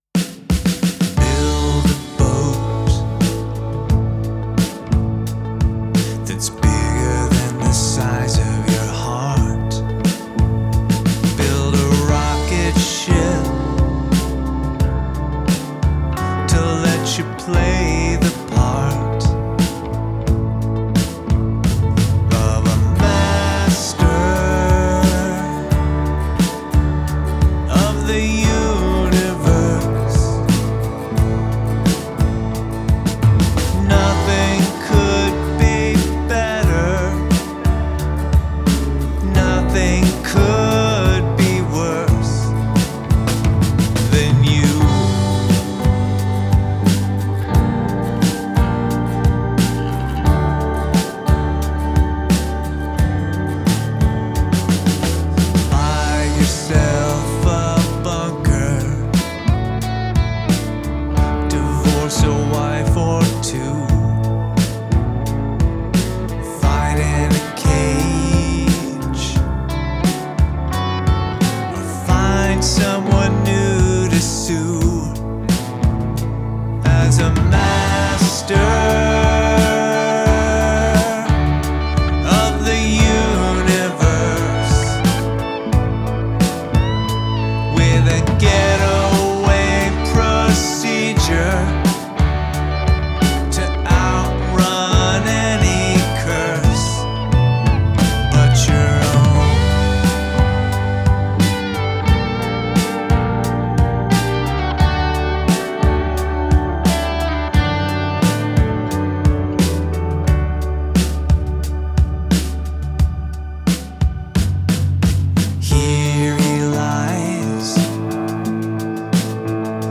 “Master of the Universe,” my demo song showing some of what the Quad Cortex mini can do.